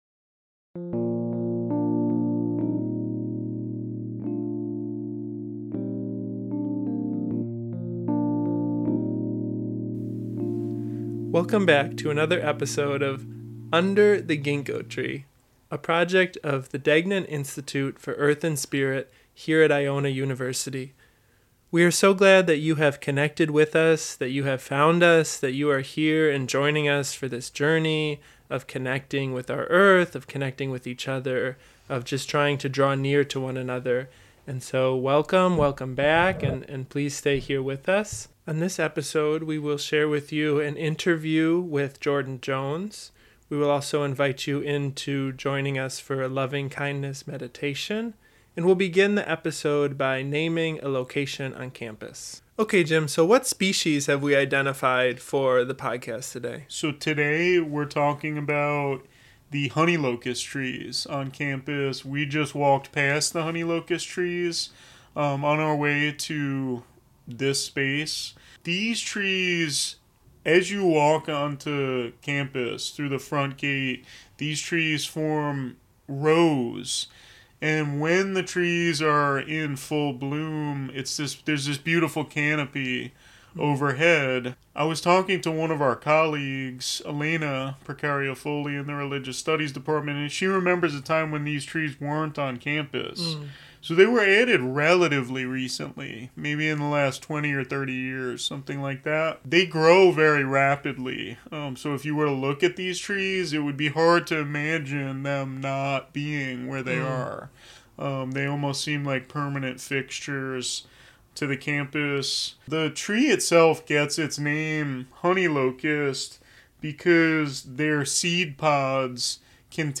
for an extended conversation